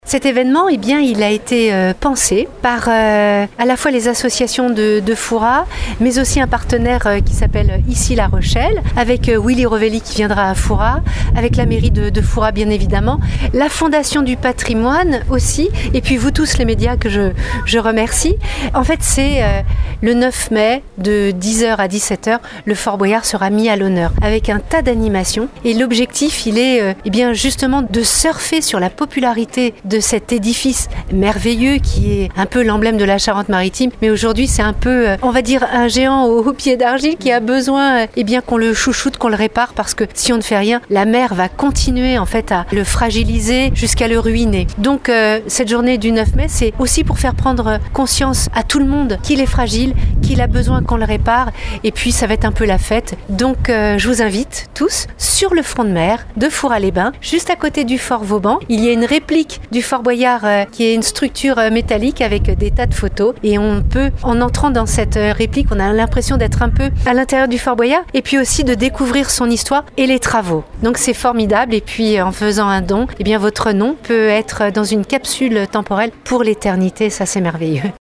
On écoute la présidente Sylvie Marcilly qui nous présente cette journée :